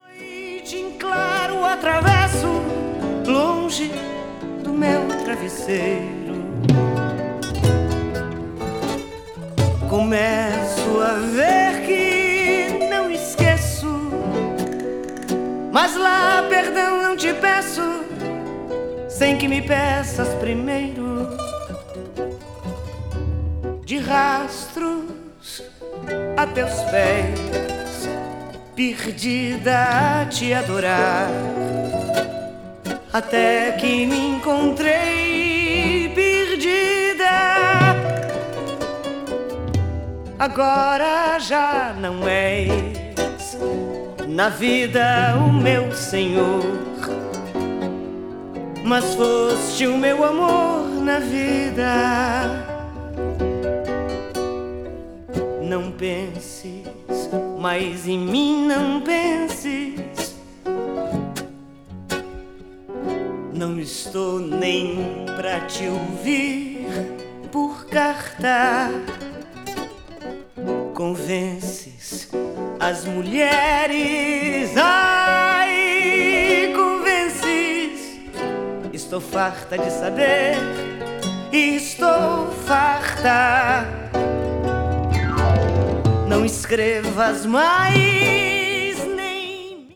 brazil   choro   folk   mpb   samba   world music